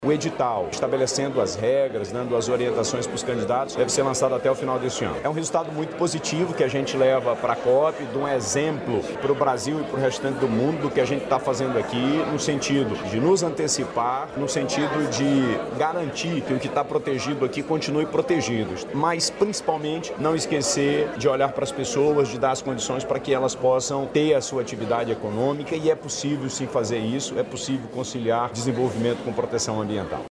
De acordo com o chefe do Executivo Estadual, a previsão é que os editais sejam lançados até o fim deste ano.